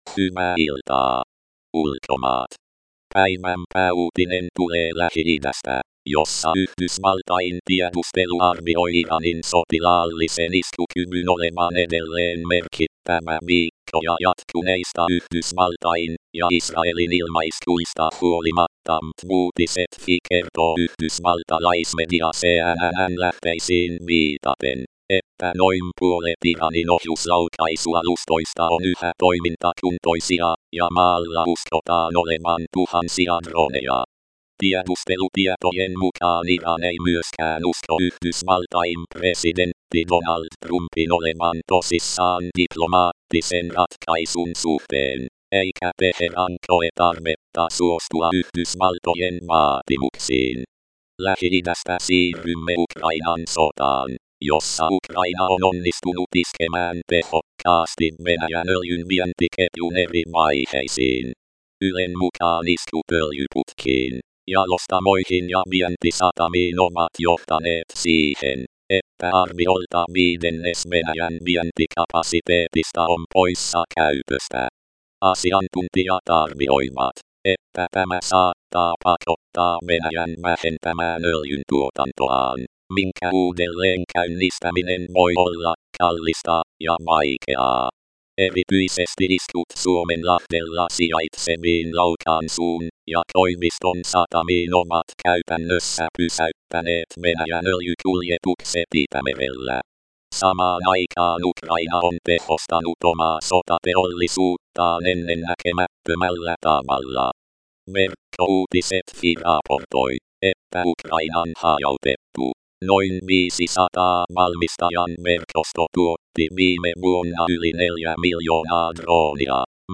Kuuntele uutiskatsaus